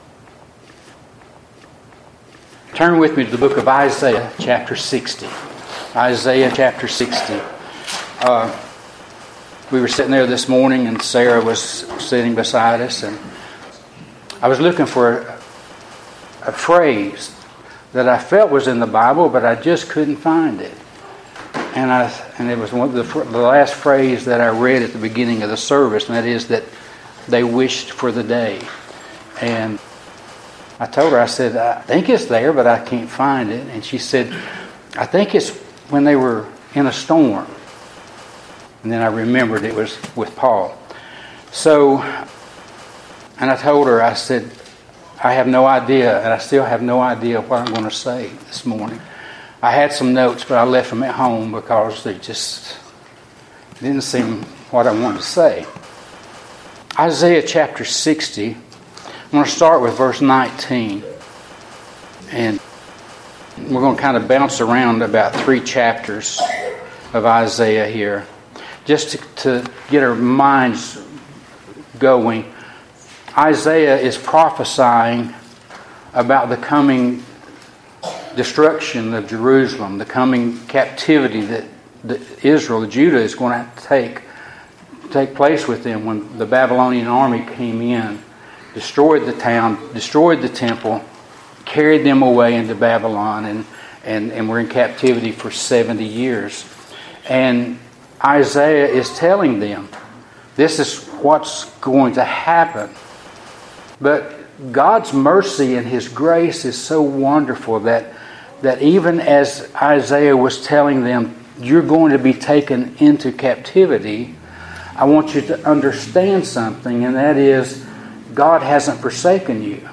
Isaiah 60:19-22, My Redeemer, My Light Feb 3 In: Sermon by Speaker